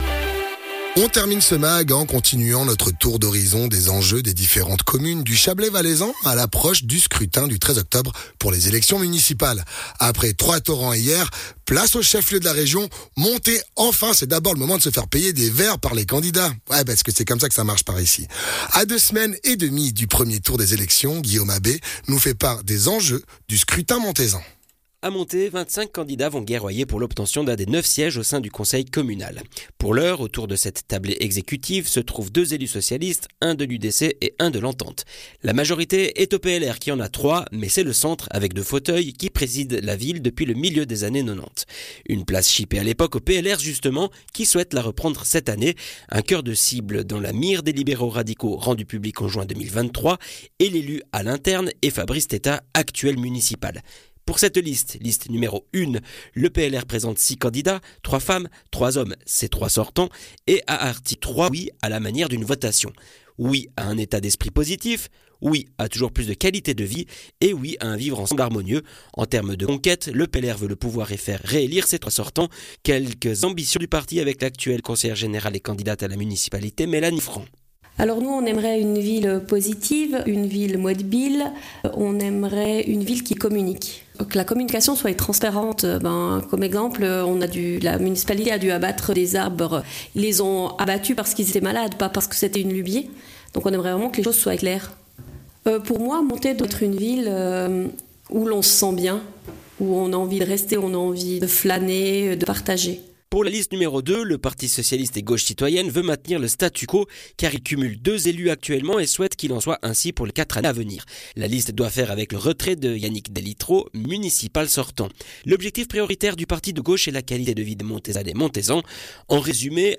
Elections communales 2024: entretien avec cinq listes et cinq candidats montheysans